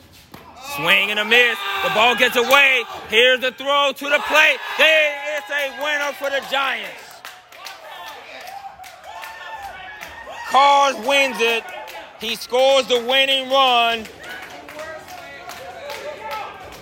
Call of the end of Secret 9 @ Gentilly Giants on March 10, 2024. Bottom of the 9th, game tied 5-5, bases loaded, two outs…